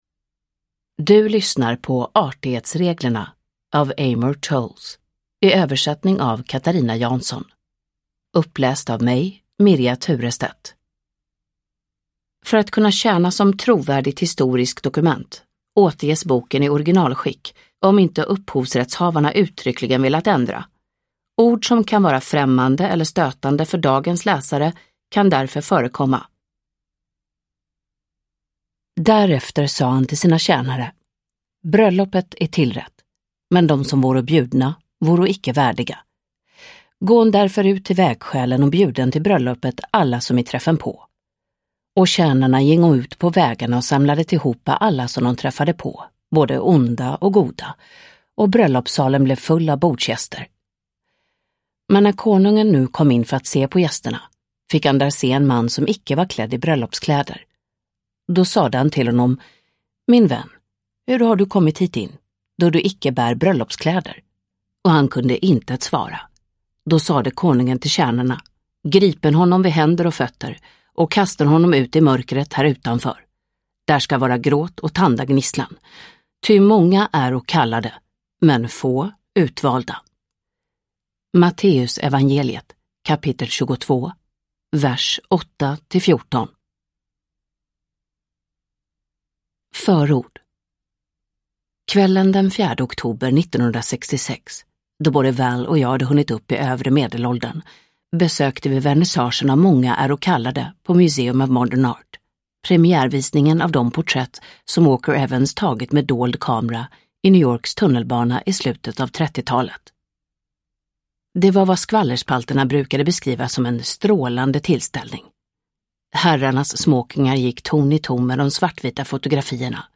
Artighetsreglerna – Ljudbok – Laddas ner